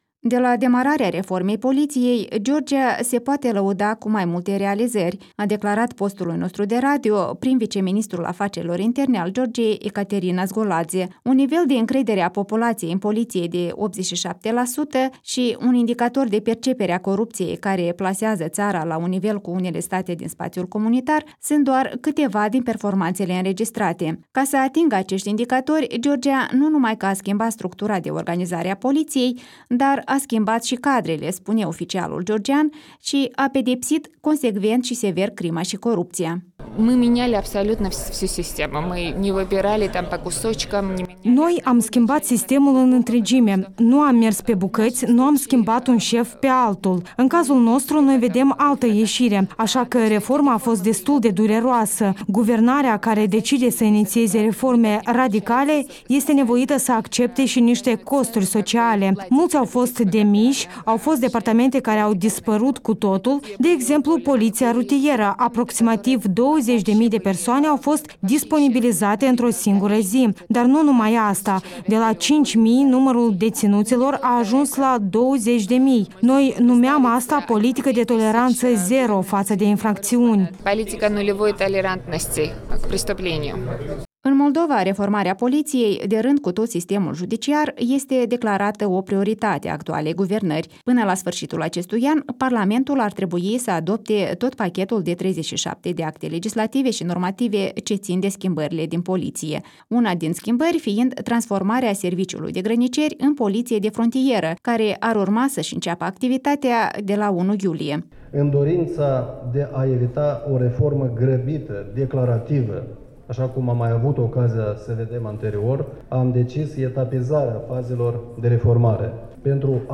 Despre experiența lor au vorbit astăzi la Chișinău, la o conferință sprijinită de ambasada Statelor Unite, mai mulți experți de la Tbilisi.